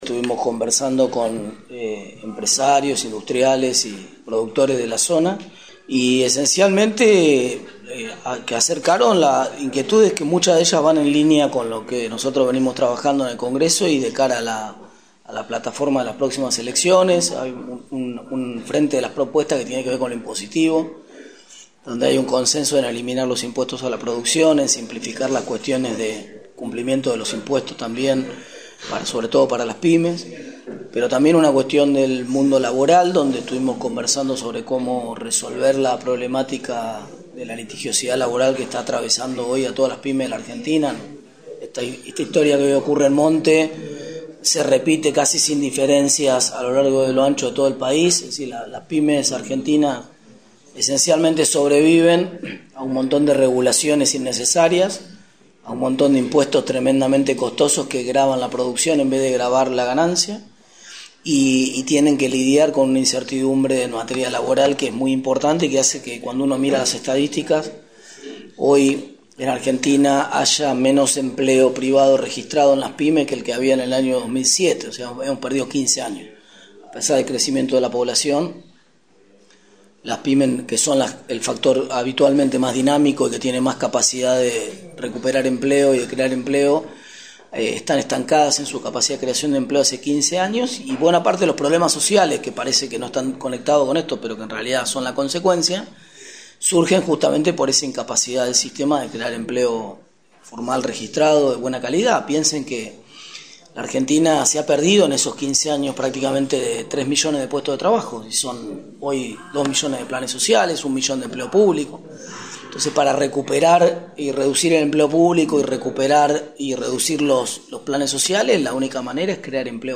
Posteriormente, se dio una conferencia de prensa integrada por